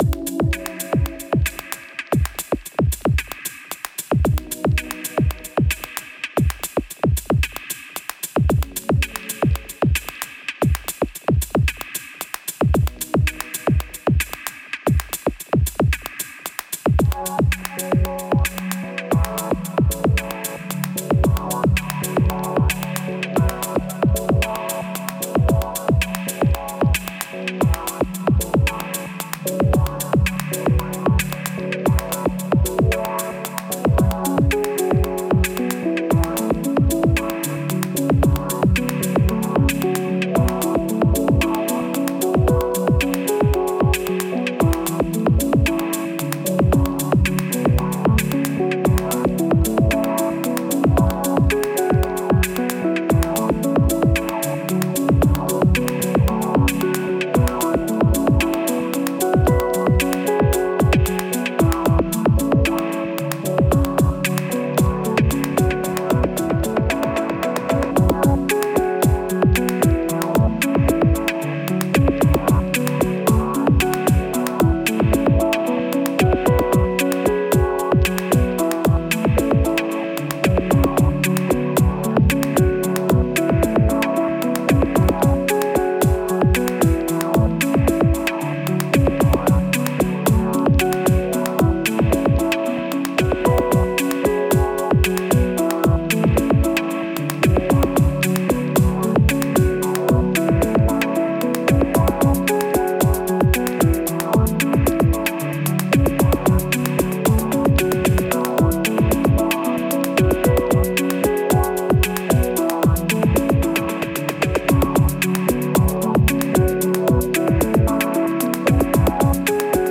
Genre: Leftfield/Dub Techno/Ambient.